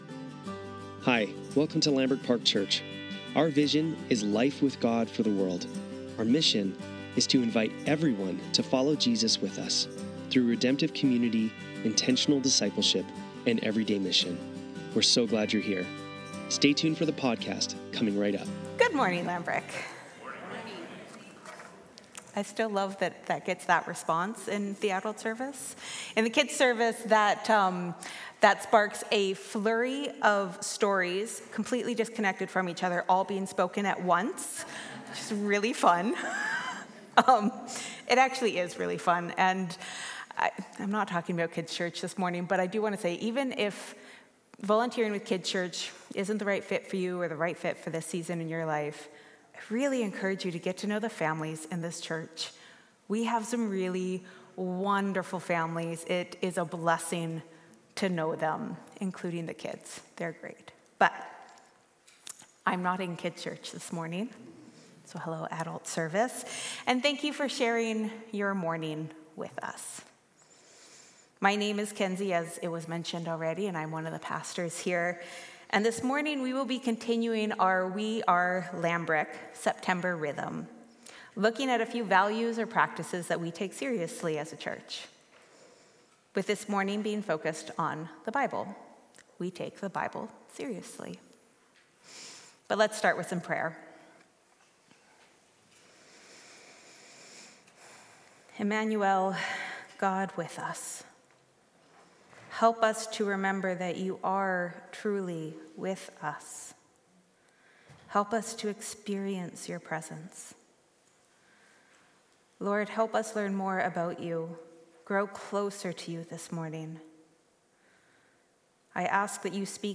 Sunday Service - September 15, 2024